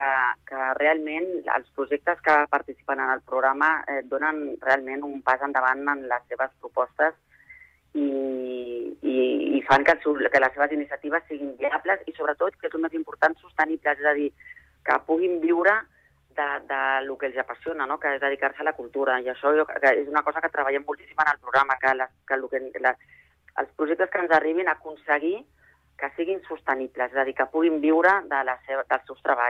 En una entrevista al Supermatí